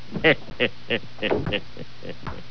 Creepy Laugh 7 Sound Effect Free Download
Creepy Laugh 7